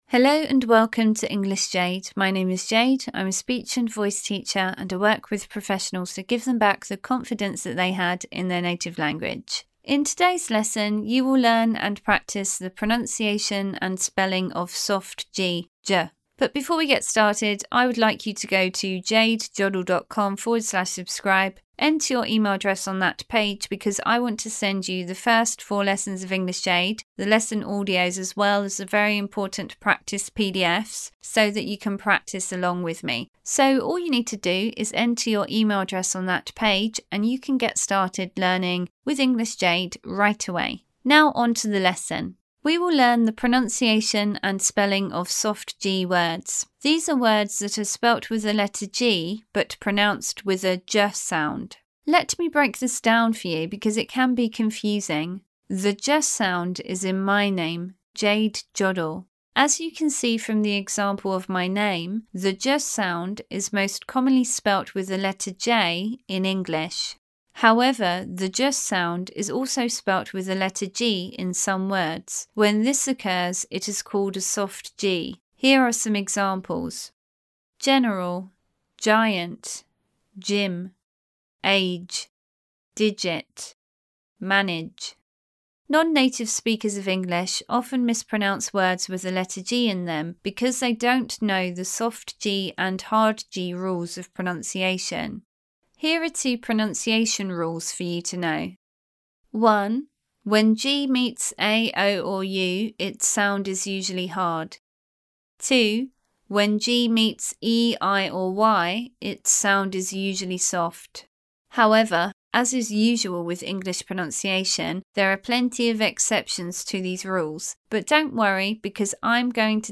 British pronunciation
These are words that are spelt with a letter ‘g’ but pronounced with a /dʒ/ sound.
The letter ‘g’ is pronounced in two ways; /g/ as in ‘get’ or /ʤ/ as in ‘gin’.